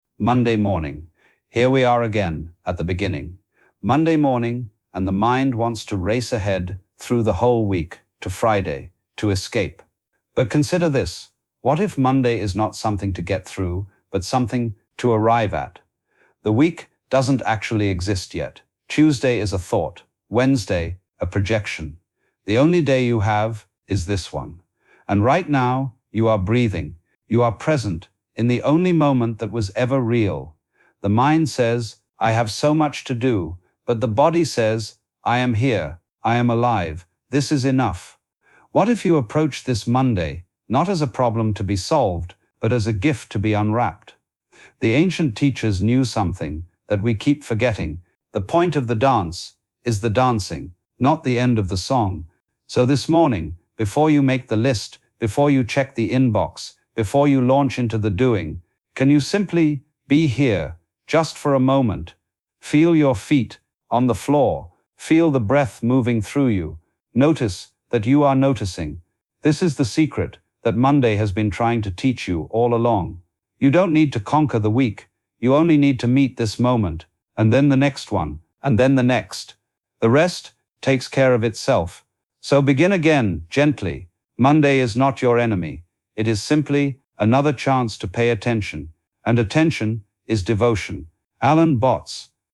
Monday Morning Meditation